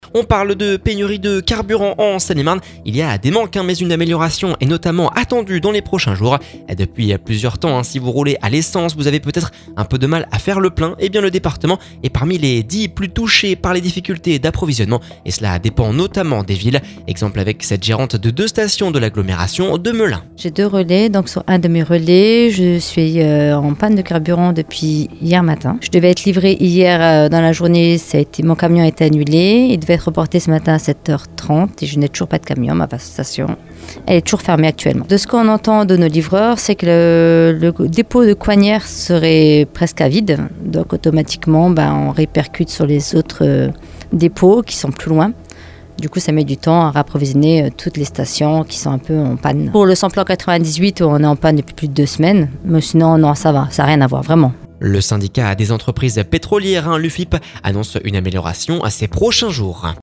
Exemple avec cette gérante de deux stations de l'agglomération de Melun.